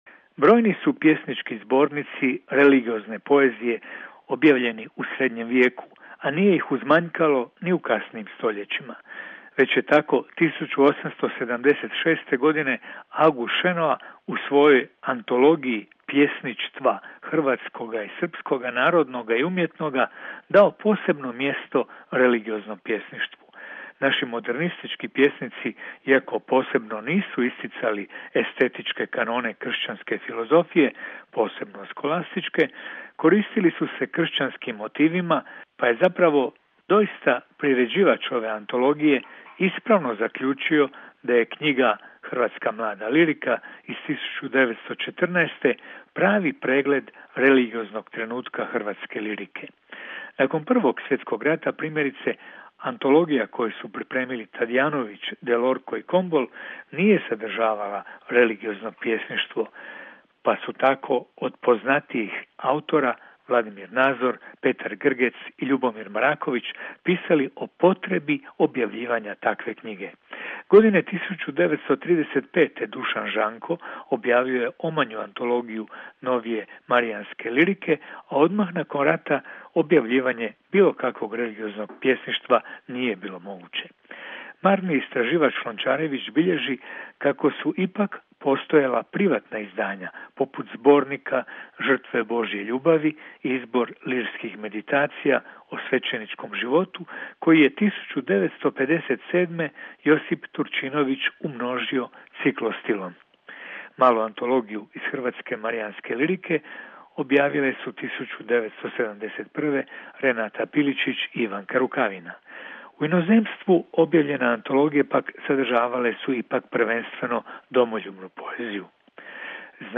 Recenzija knjige